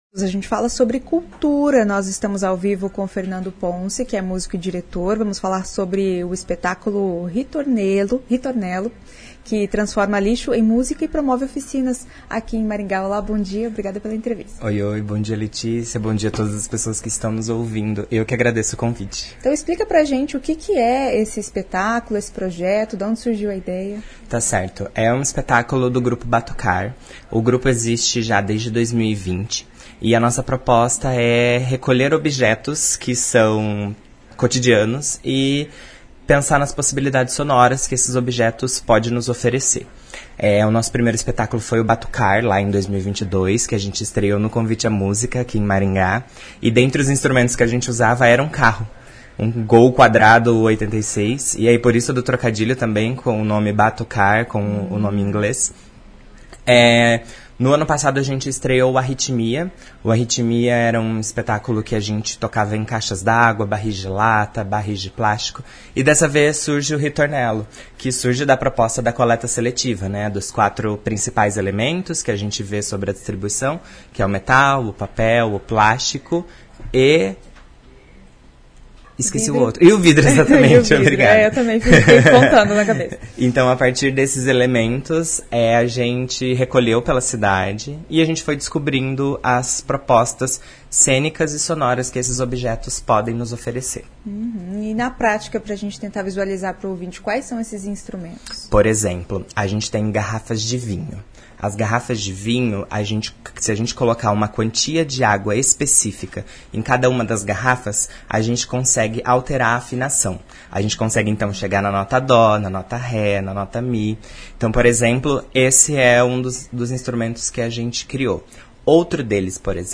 Quais materiais podem virar instrumentos musicais? Como o lixo se transforma em música? Ouça a entrevista.